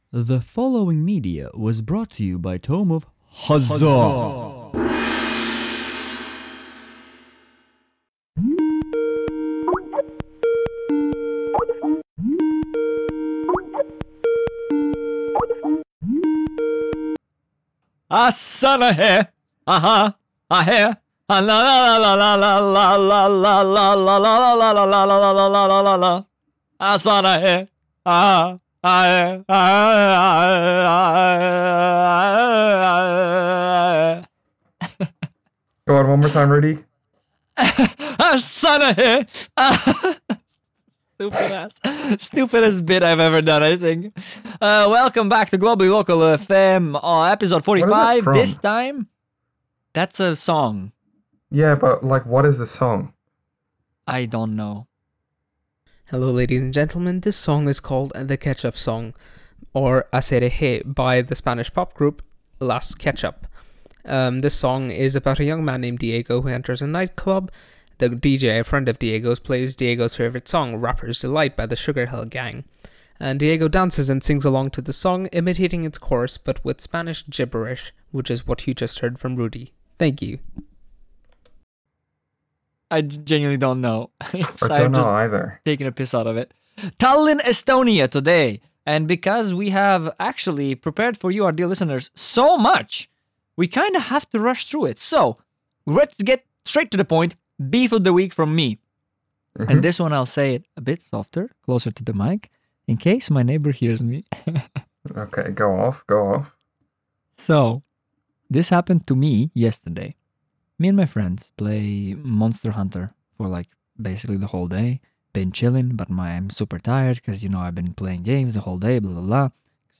Globally Local FM is a travelling radio and news show, which spotlights a new corner of the globe every week!